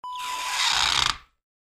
Twist squeeze sound effect .wav #1
Description: Tightening rubber sealed object
A beep sound is embedded in the audio preview file but it is not present in the high resolution downloadable wav file.
twist-squeeze-preview-1.mp3